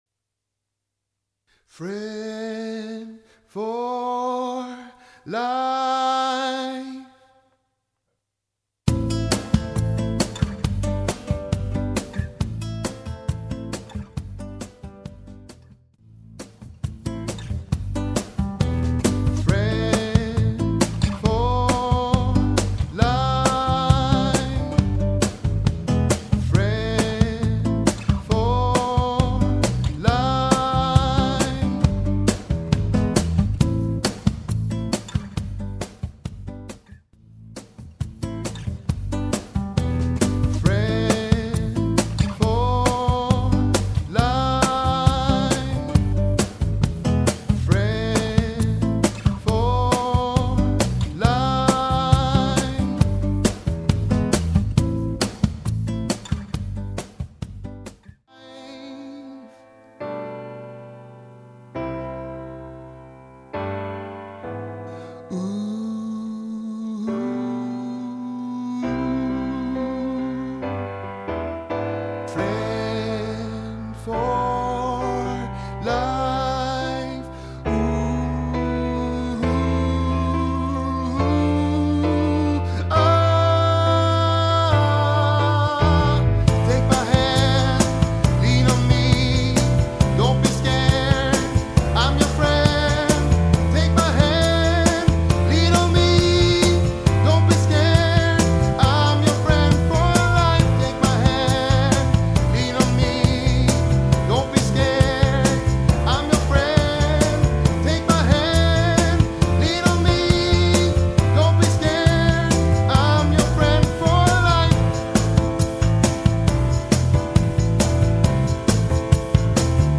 Friends_for_life_Tenor_KLIPPT_VERSION.mp3